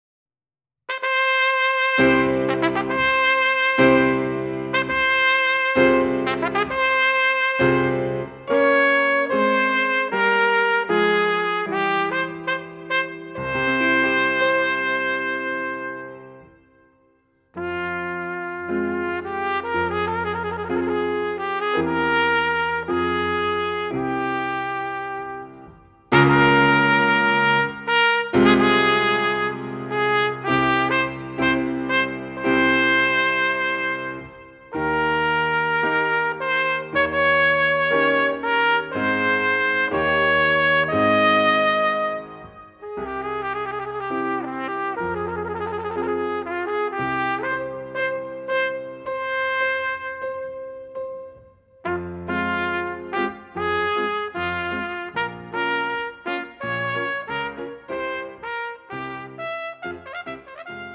Horn und Klavier Schwierigkeit